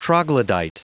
Prononciation du mot troglodyte en anglais (fichier audio)
Vous êtes ici : Cours d'anglais > Outils | Audio/Vidéo > Lire un mot à haute voix > Lire le mot troglodyte
Prononciation du mot : troglodyte